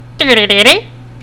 DELIBIRD.mp3